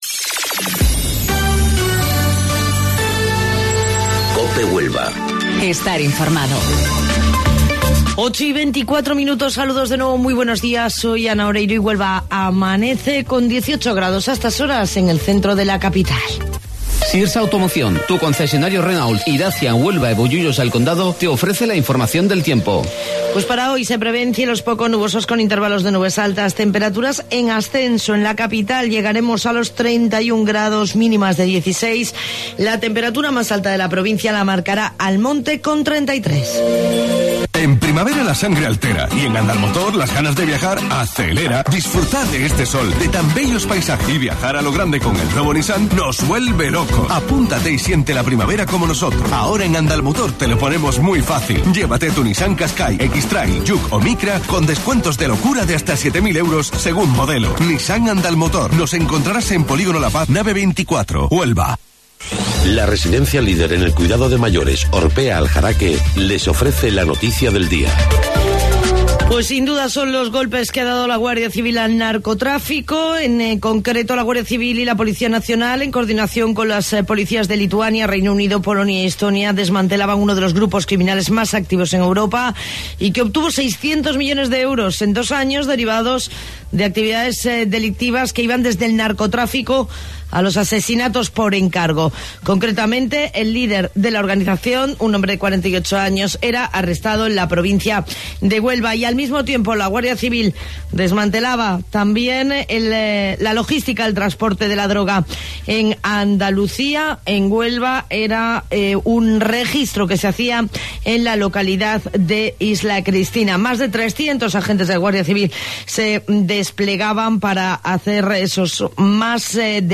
AUDIO: Informativo Local 08:25 del 23 de Mayo